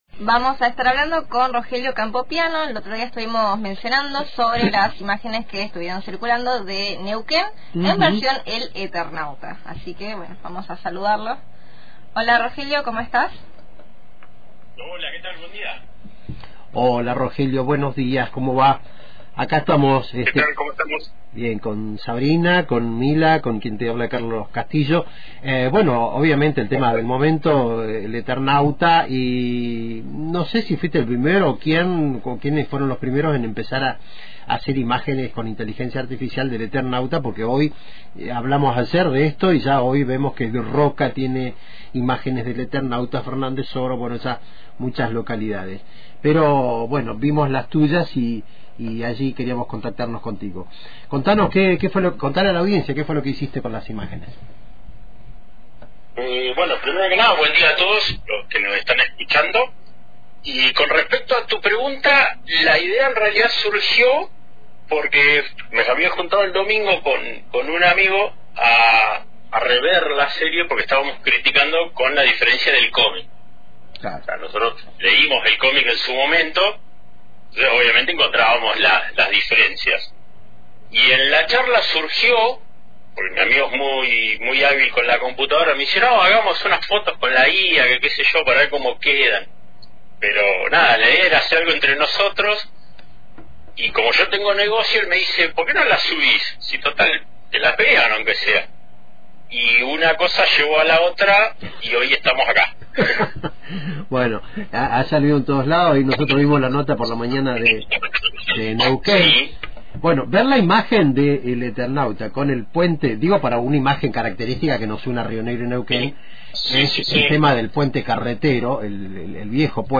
Escucha la entrevista completa acá abajo: